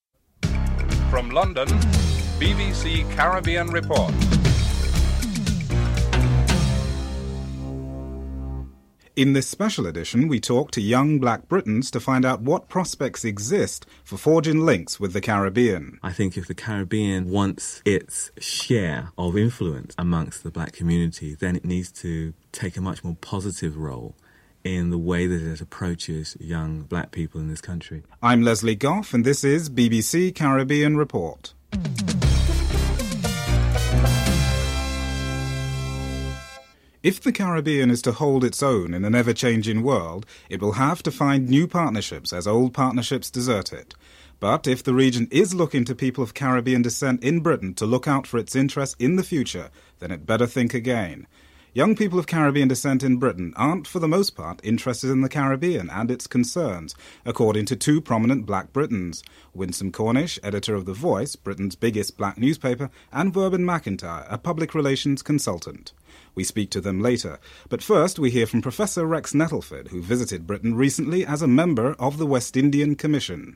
A young businessman notes that they are misinformed about what happens in the Caribbean regarding the standard of education, health, living conditions and deprivation.